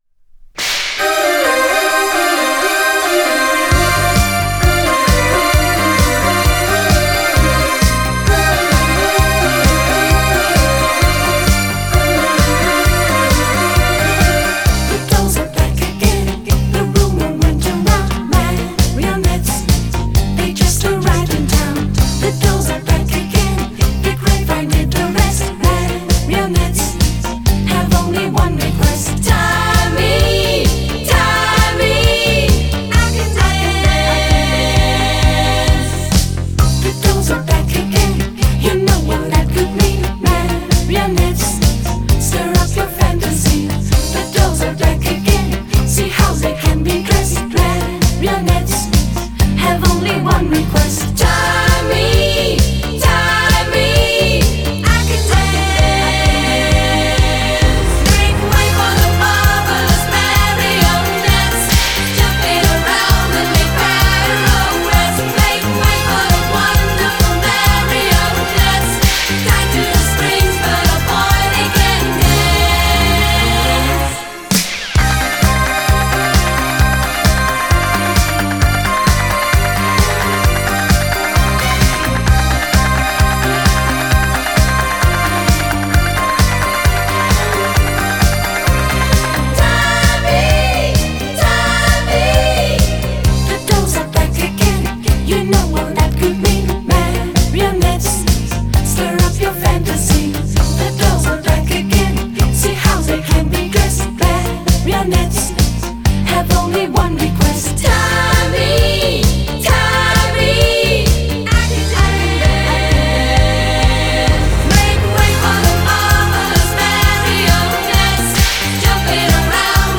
И попса была как-то красивопопсовее!